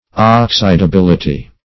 Search Result for " oxidability" : The Collaborative International Dictionary of English v.0.48: Oxidability \Ox`i*da*bil"i*ty\, n. [Cf. F. oxydabilit['e].] Capability of being converted into an oxide.